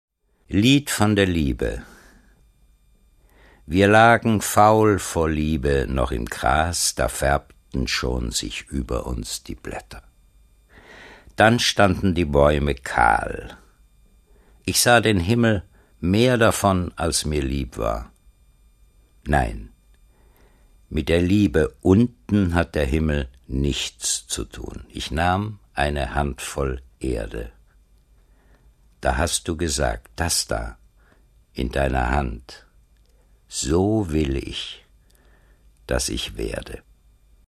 Kurz informiert liefert täglich die wichtigsten Nachrichten zu IT, Mobilem, Gadgets, Netzpolitik & Wissenschaft – heute mit der synthetischen Stimme